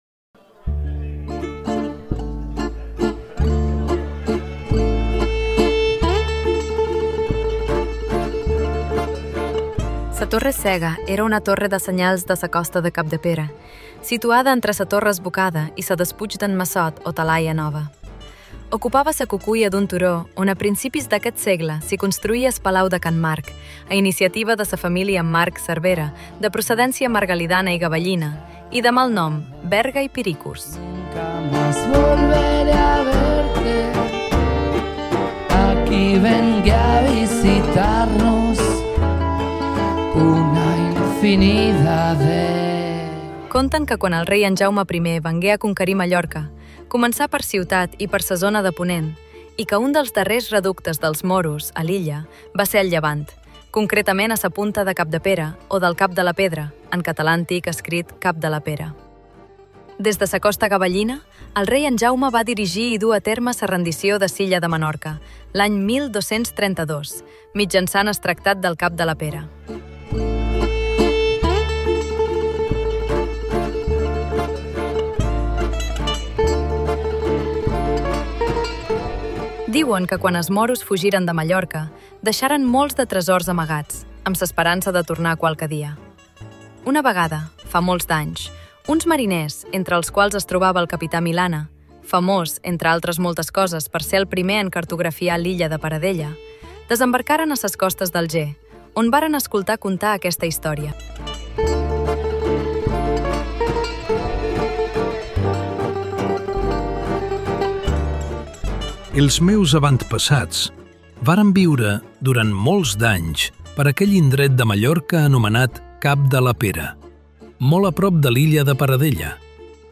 Narració oral musicada sobre el capità Milana.